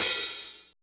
NICE OHH.wav